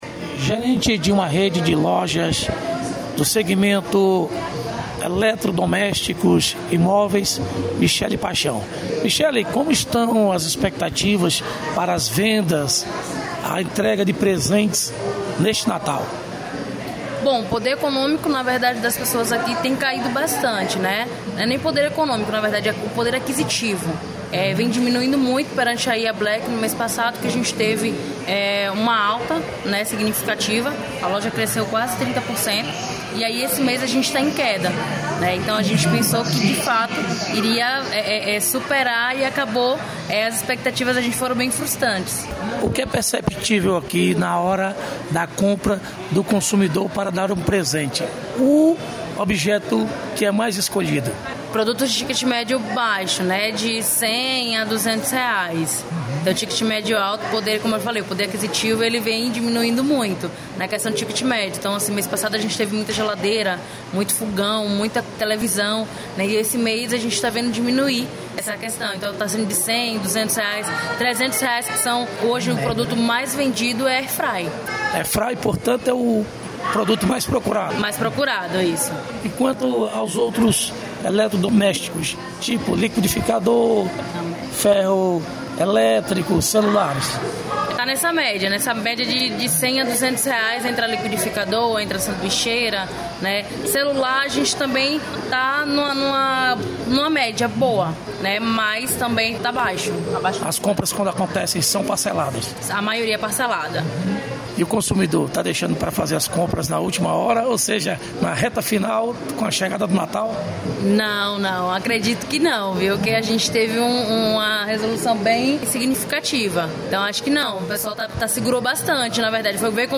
Reportagem: expectativa de vendas e compras no comércio de CFormoso